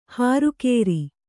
♪ hāru kēri